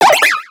Cri d'Emolga dans Pokémon X et Y.